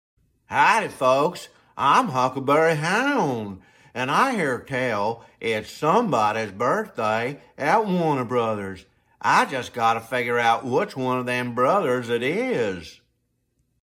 Jeff Bergman the voice of sound effects free download By jeffbergman 2 Downloads 30 months ago 13 seconds jeffbergman Sound Effects About Jeff Bergman the voice of Mp3 Sound Effect Jeff Bergman the voice of Bugs Bunny in “Space Jam: A New Legacy” salutes Warner Bros.'s 100th anniversary with 100 impersonations. 🎬 Here is day 24: Huckleberry Hound salutes 100 years to Warner Bros.